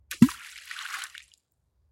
feat: water sounds instead of bells
splash-medium.mp3